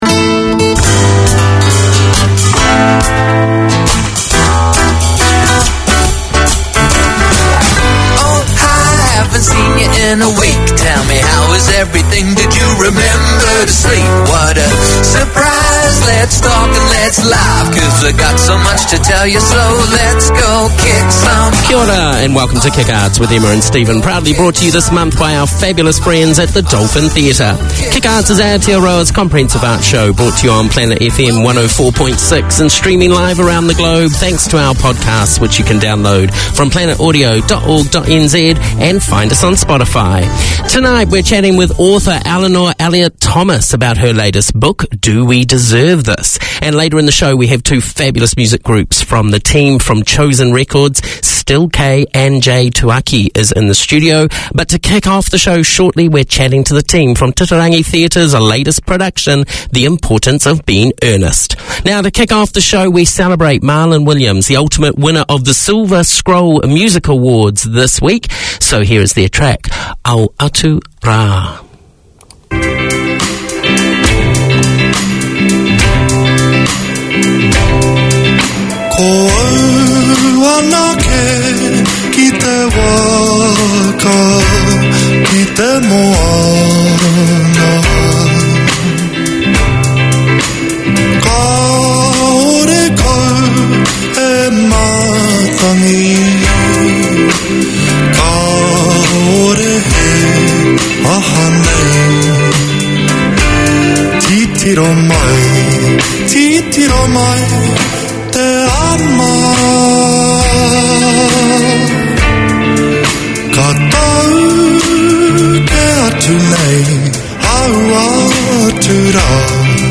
Radio made by over 100 Aucklanders addressing the diverse cultures and interests in 35 languages.
A comprehensive arts show featuring news, reviews and interviews covering all ARTS platforms: film, theatre, dance, the visual arts, books, poetry, music ... anything that is creative.